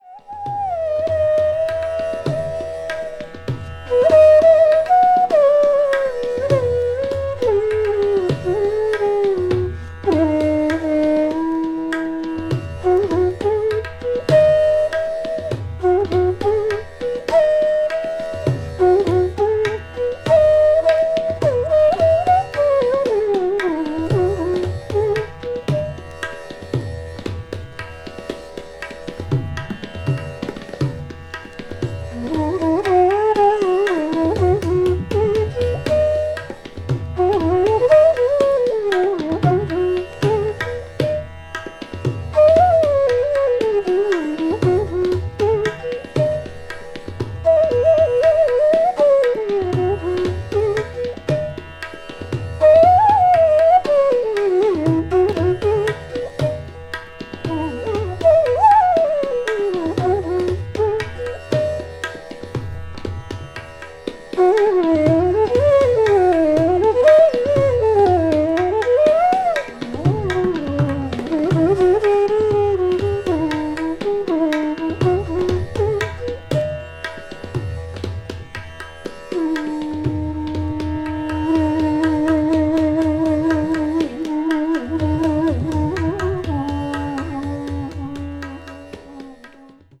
No. 18 Flute. Raga Desh Tala Jhampa Tala
classical   ethnic music   india   oriental   traditional